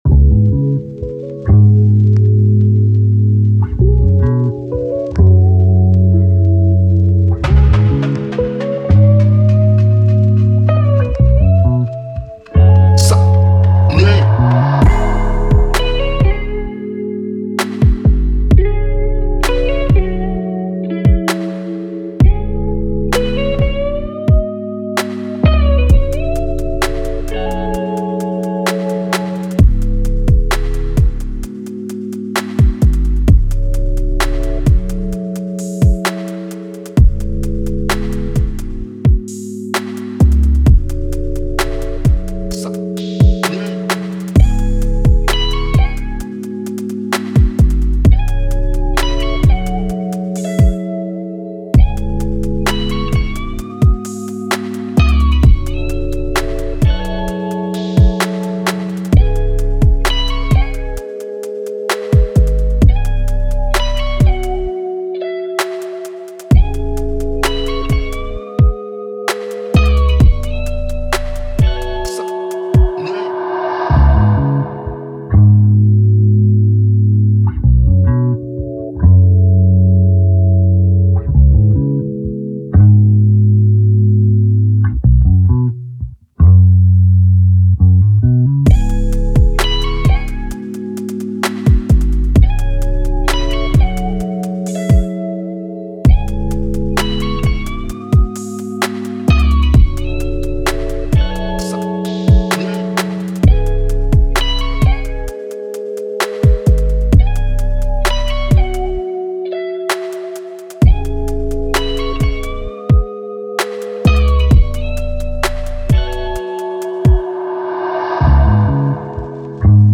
Classic, Energetic, Chill, Emotional
Eletric Guitar, Drum, Strings, Bass